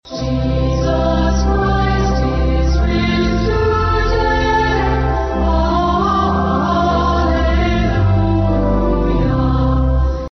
Easter is full of traditions, and a local tradition took place again Sunday morning as Messiah Lutheran Church had its annual drive-in Easter worship service outside the Fairgrounds Anderson Building.
1026-messiah-music.mp3